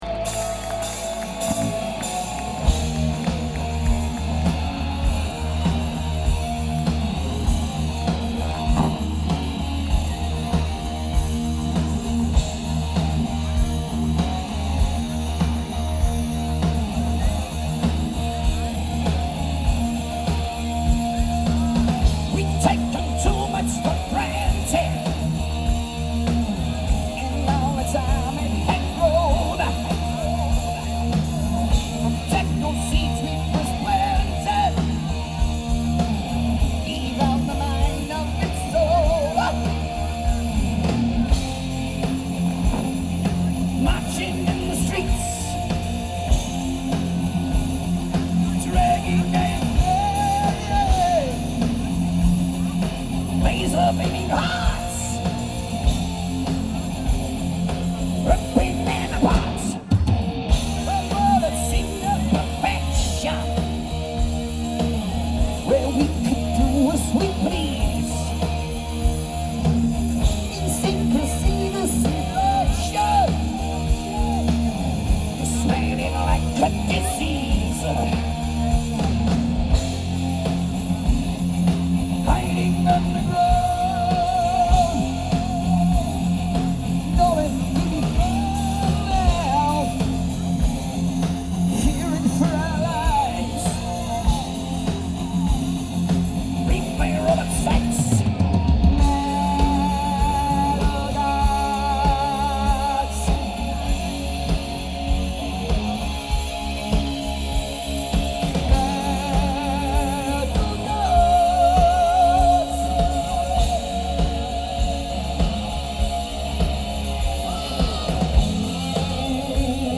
quality here is very good.